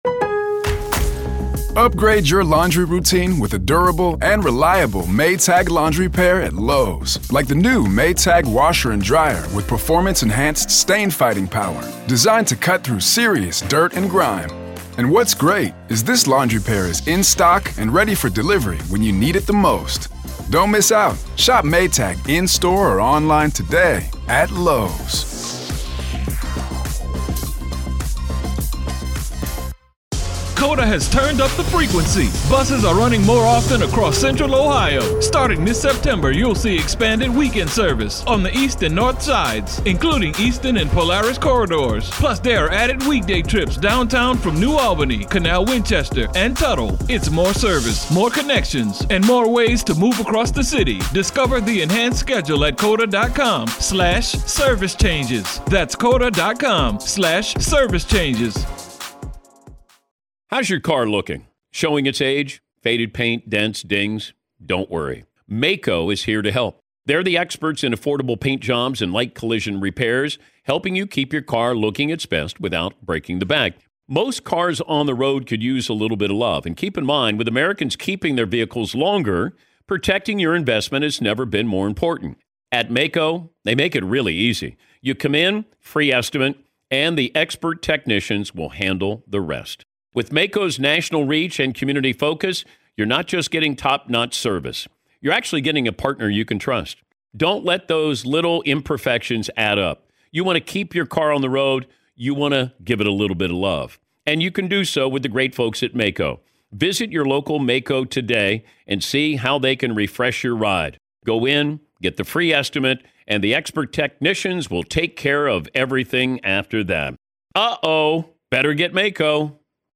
the conversation shifts from testimony to analysis — and the insights are chilling.
his co-hosts